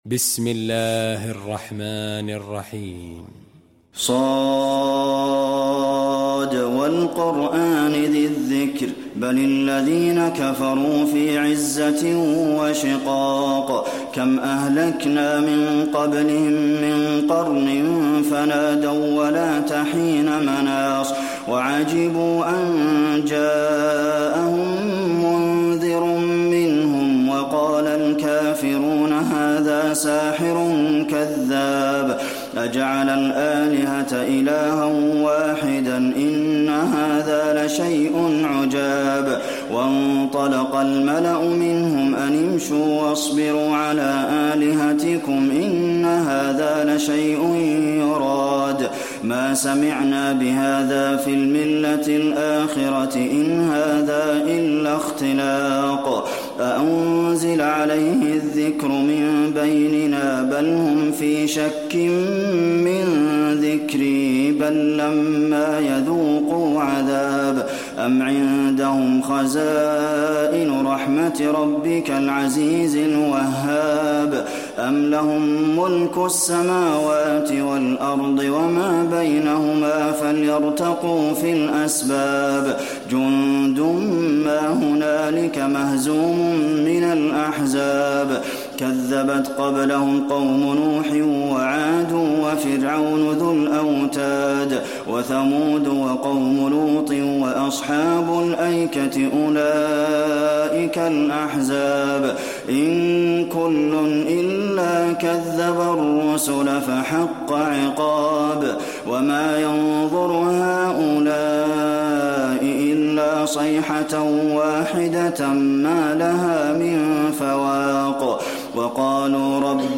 المكان: المسجد النبوي ص